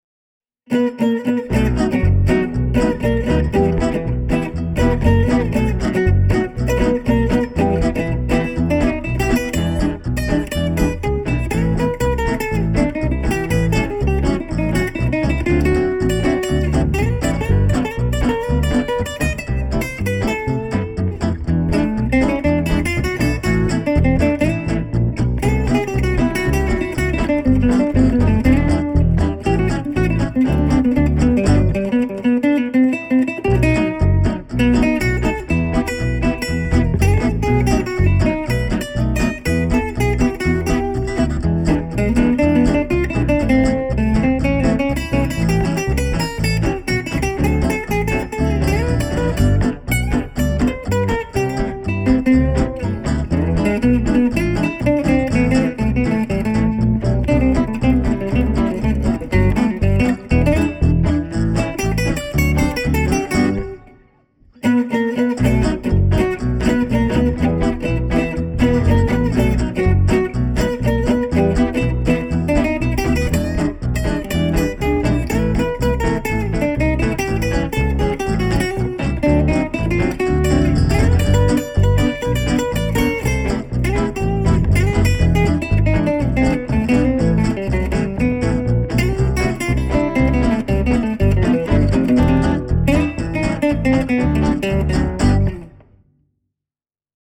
Enregisté au Studio Angström fin 2008